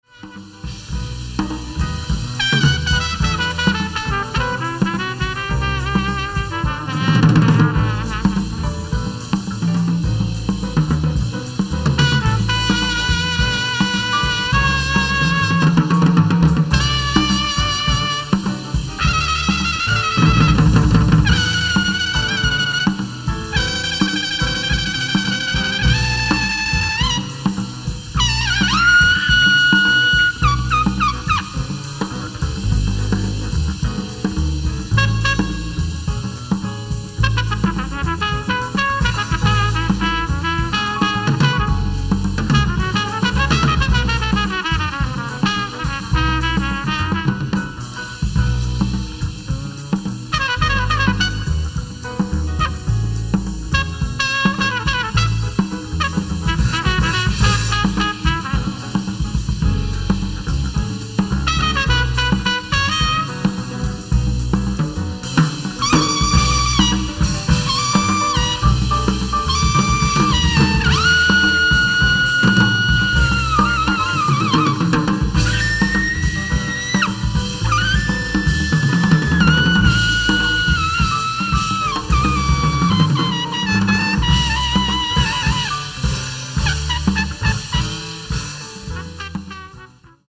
ライブ・アット・ノースシー・ジャズ、オランダ 07/17/1982